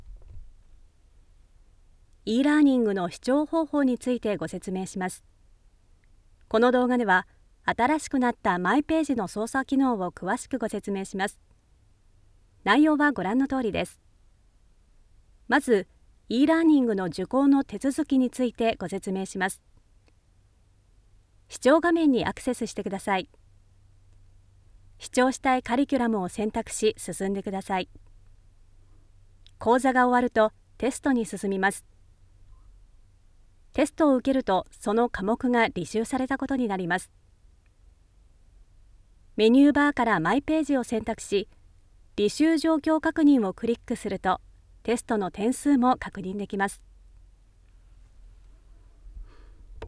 1. E-Learning 00:50
If you’re looking for a voiceover that is based in japan i have many years of experience, and i can help you with your next project.